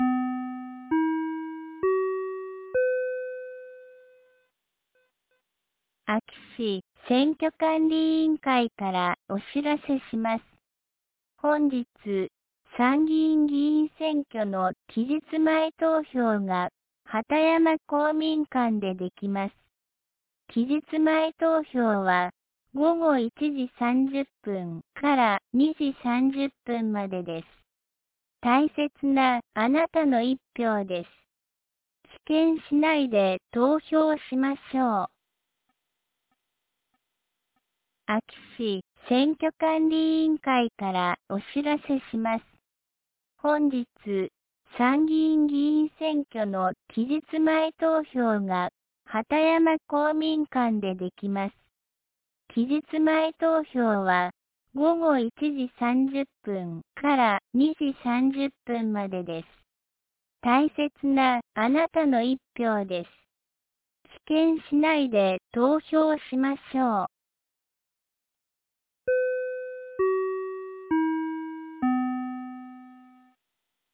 2025年07月17日 09時05分に、安芸市より畑山へ放送がありました。